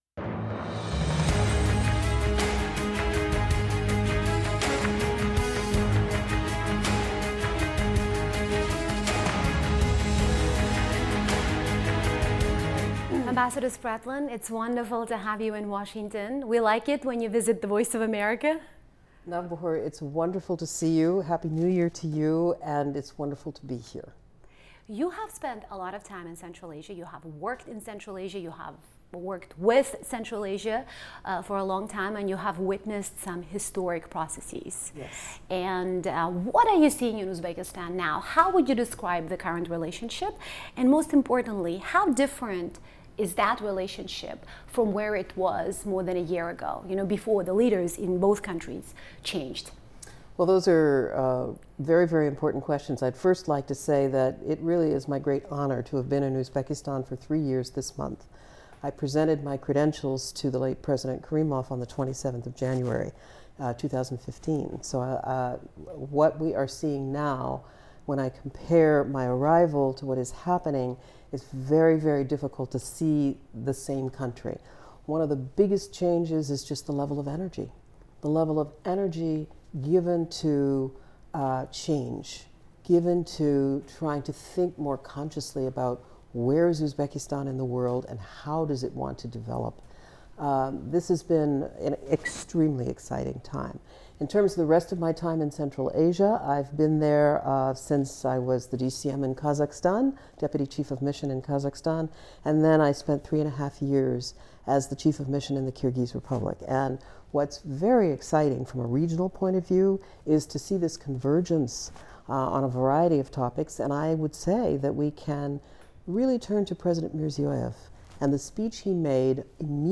US-Uzbekistan: Interview with Ambassador Pamela Spratlen